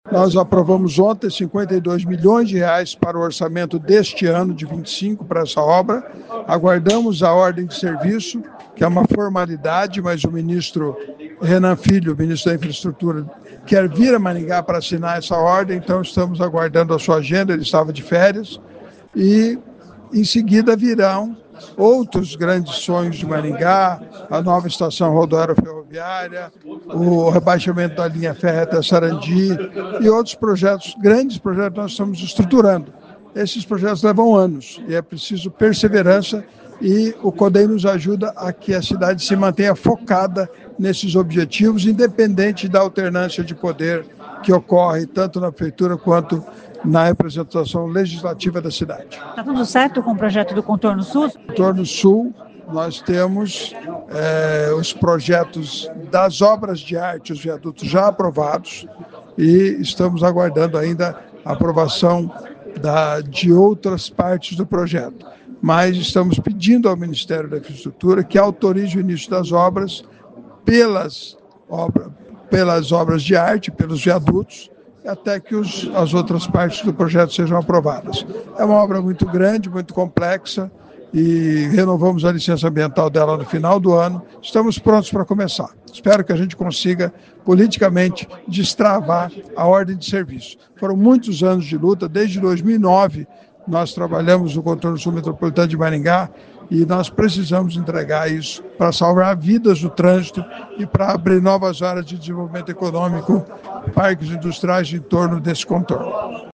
Ouça o que diz o deputado.